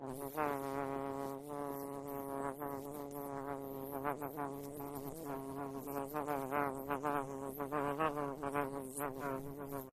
bee_buzz.ogg